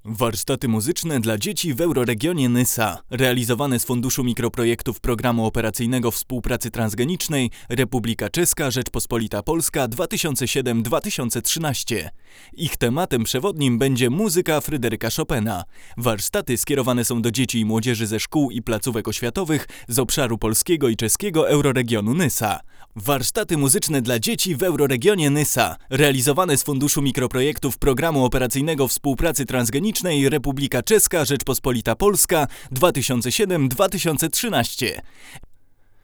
Głos jest wyraźny, soczysty, czysty. Ale jednocześnie wzrosła wrażliwość na sybilanty, a braki w dolnym zakresie spowodowały, że wokale tracą indywidualne brzmienie.